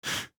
deer-v1.ogg